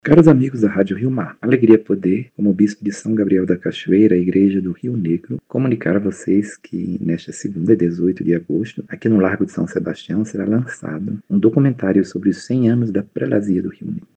Sonora-2-–-Dom-Vanthuy-Neto.mp3